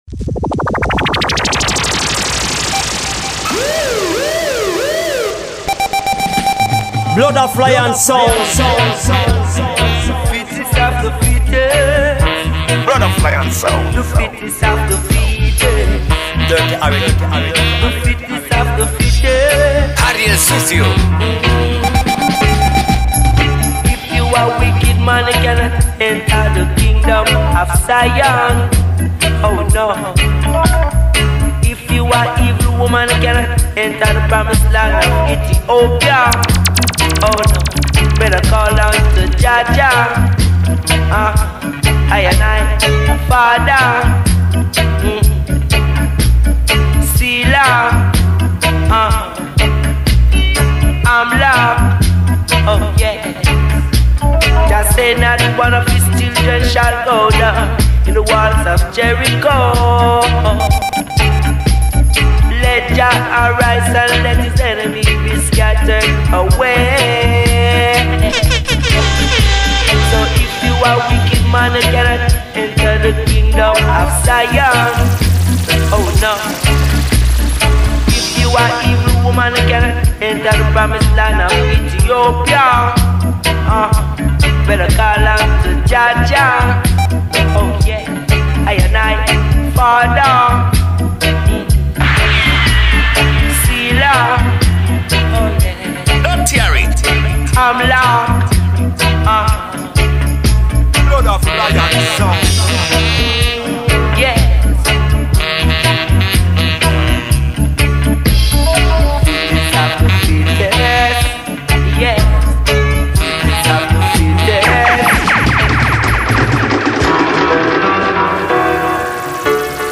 More than 1 hour of jah music